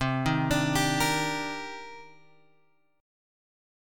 C Minor 9th